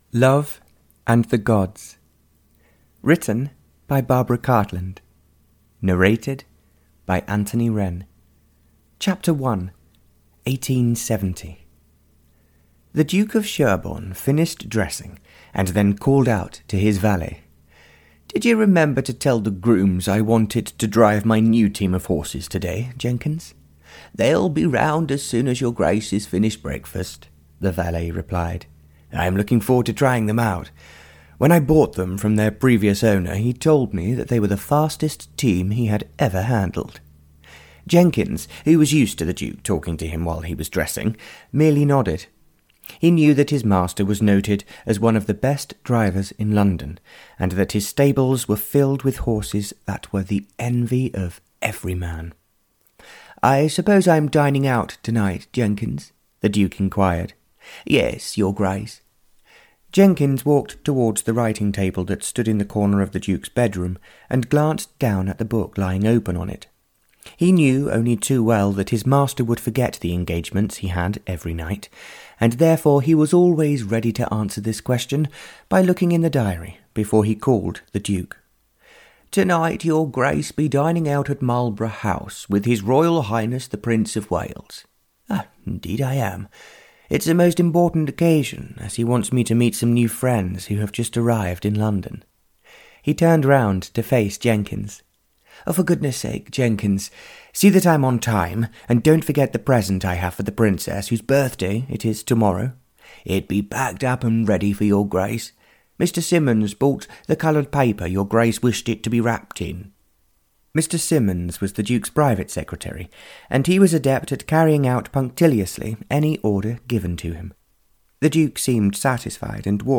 Audio knihaLove and the Gods (Barbara Cartland's Pink Collection 95) (EN)
Ukázka z knihy